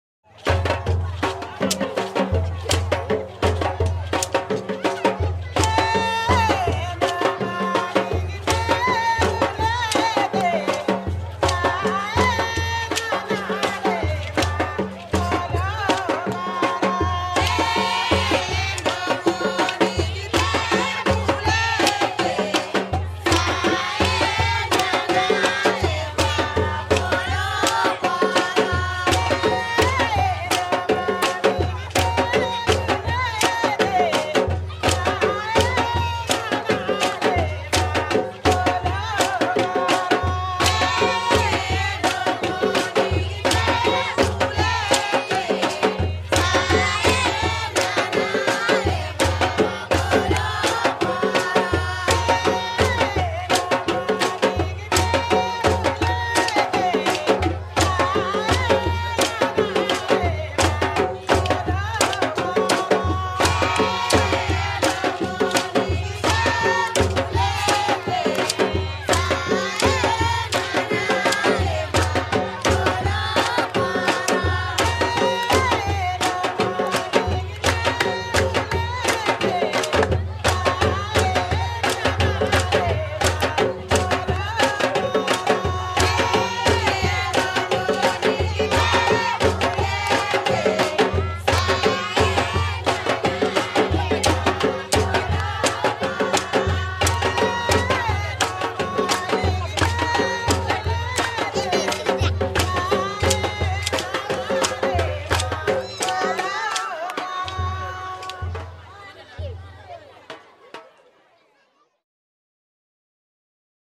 Rhythm to be heard and seen: a Bamana masquerade in Mali